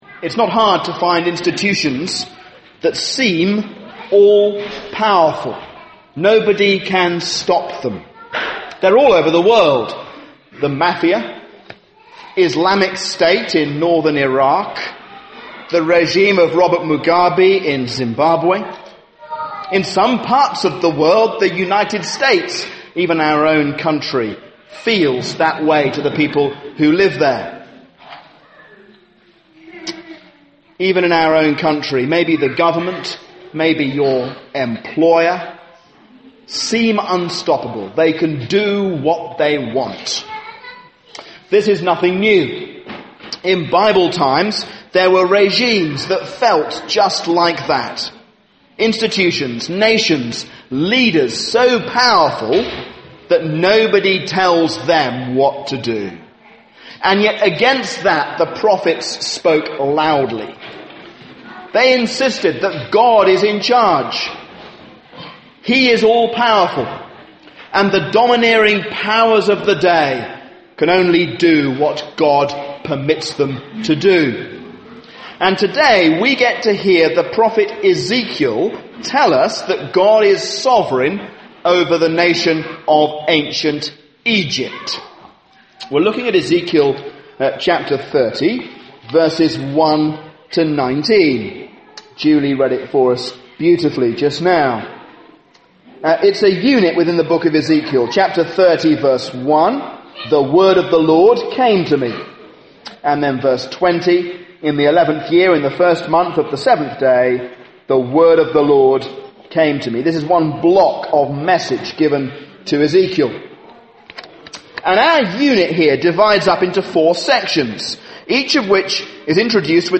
A sermon on Ezekiel 30